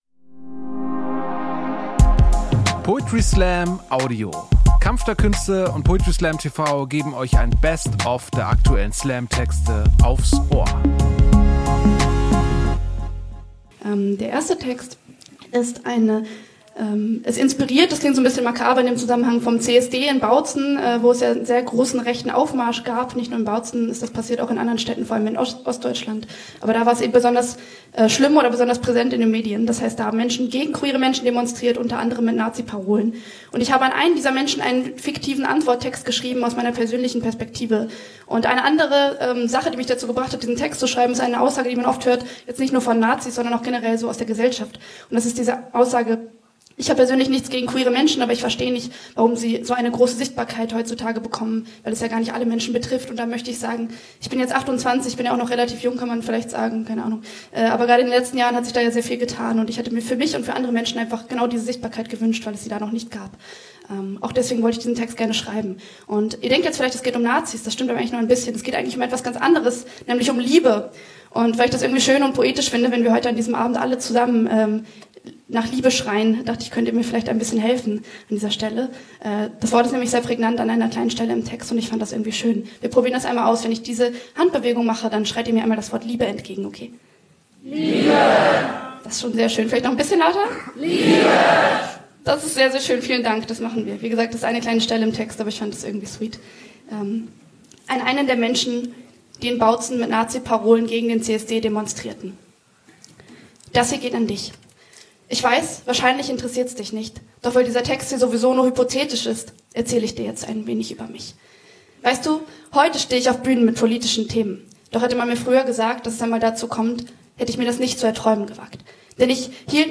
Stage: Ernst Deutsch Theater